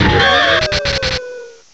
cry_not_electivire.aif